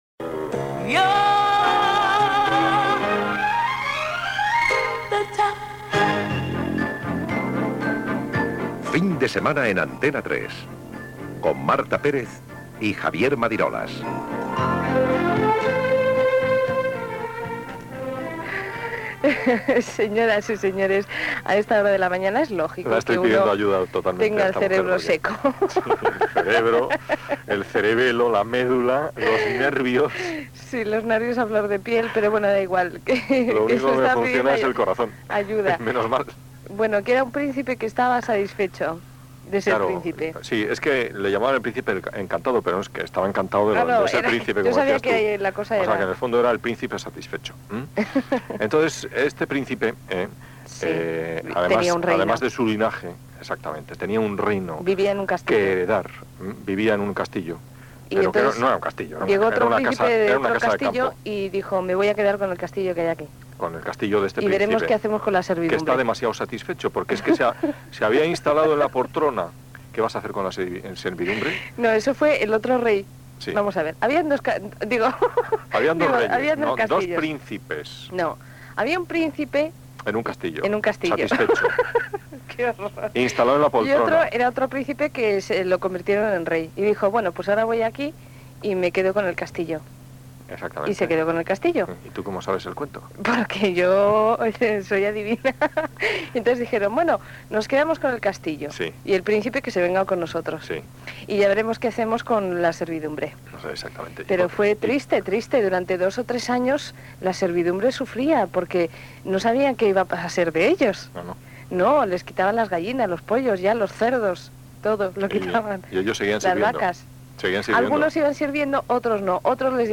Indicatiu del programa, explicació d'un conte infantil que simbolitza l'absorció d'Antena 3 Ràdio pel Grupo Prisa.
Entreteniment
Fragment de la derrera emissió d'Antena 3 radio del 18 de juny de 1994.